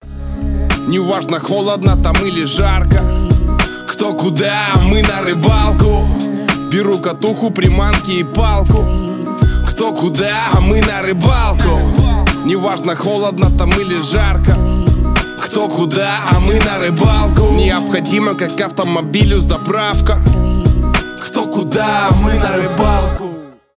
рэп , качающие